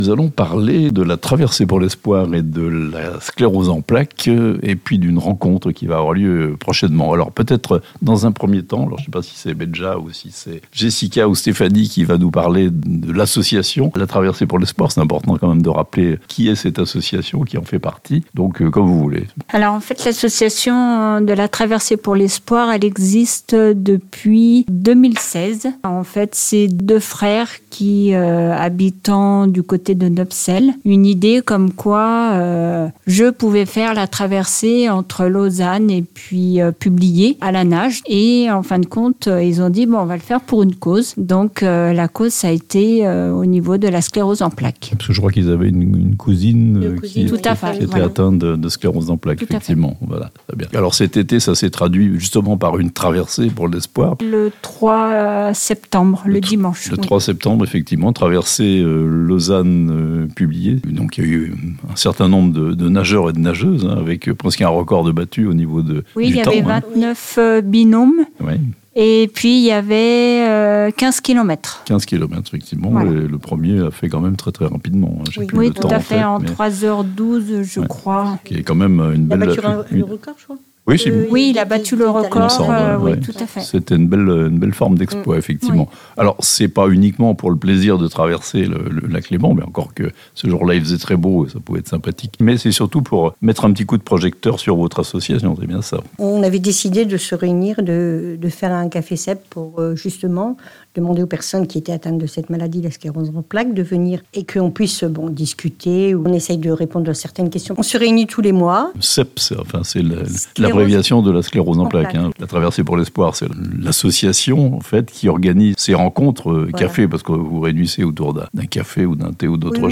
Amphion/Publier : "Les Cafés SEP" pour parler de la sclérose en plaques (interviews)
Présentation de l'association et des "Cafés SEP" par quelques membres de l'associaton La Traversée pour l'Espoir.